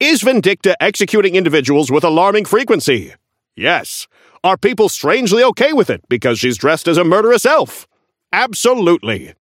Newscaster_seasonal_hornet_unlock_01_alt_01.mp3